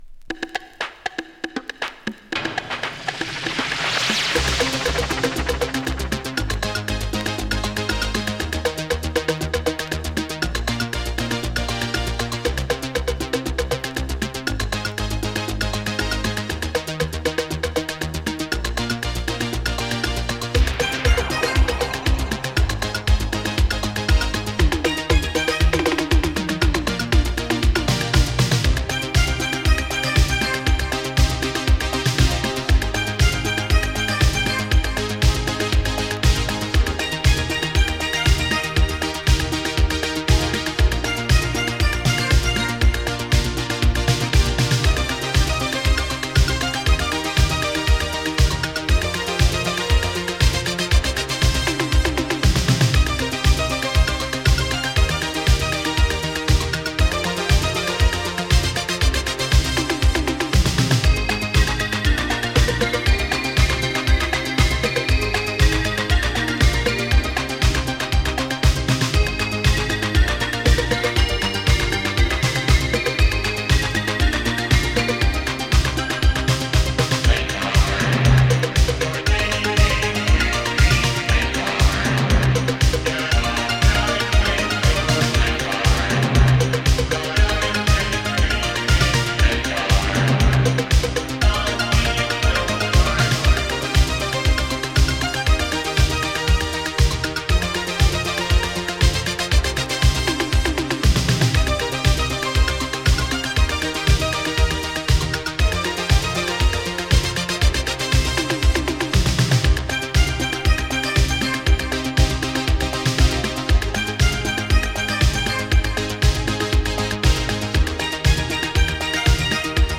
イタリア産エレクトロ・ディスコ！
【ITALO DISCO】